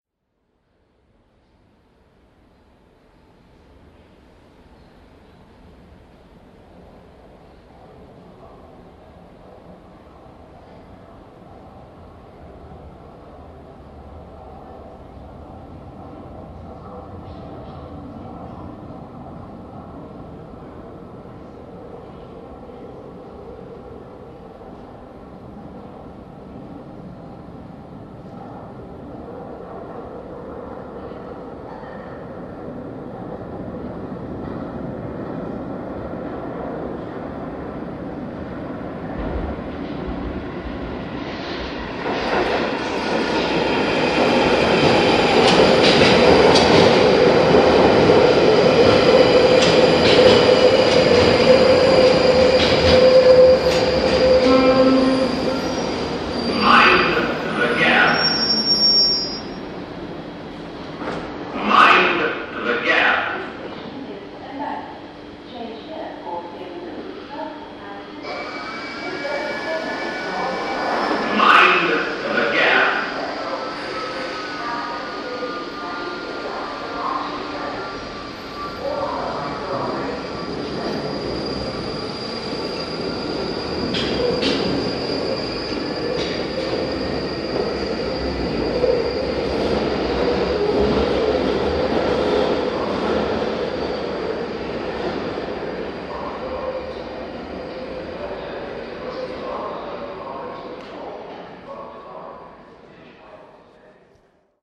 Field recording from the London Underground by The London Sound Survey.